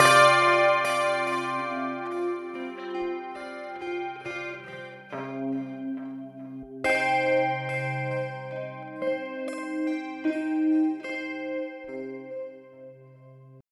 Clang2_70_C.wav